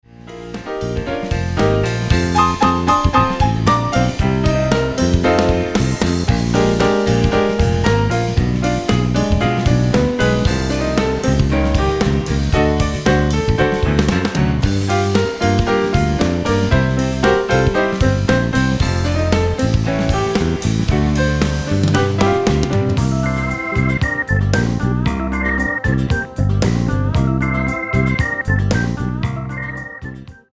115 BPM